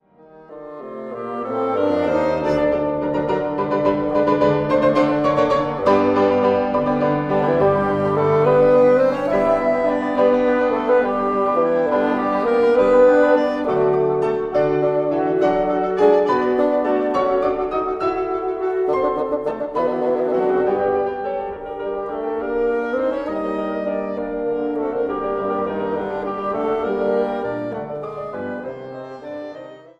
Kammermusik für Bläser und Klavier